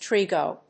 /ˈtrigo(米国英語), ˈtri:gəʊ(英国英語)/